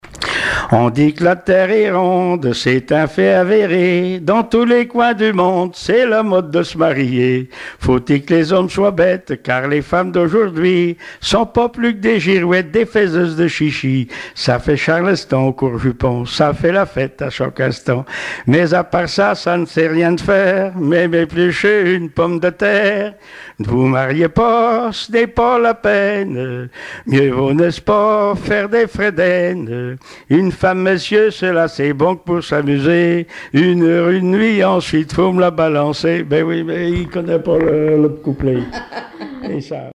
Genre strophique
Témoignages et chansons traditionnelles et populaires
Pièce musicale inédite